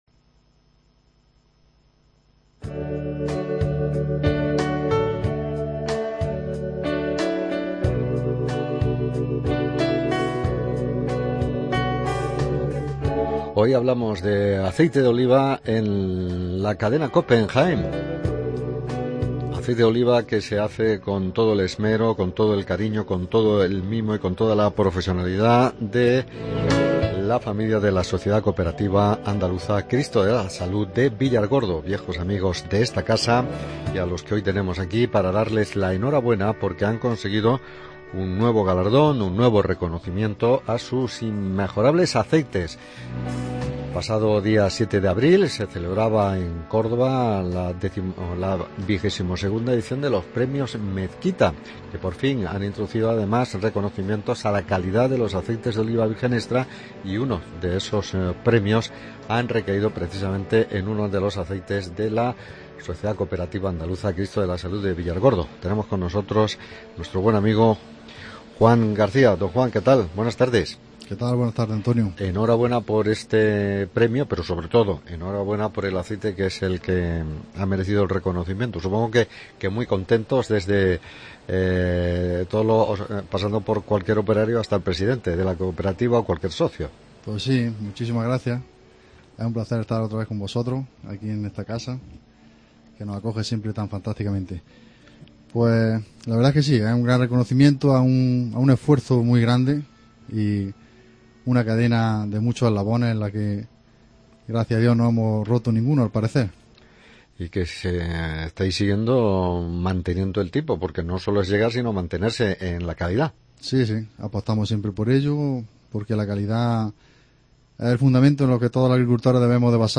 ENTREVISTA ACEITE CRISTO DE LA SALUD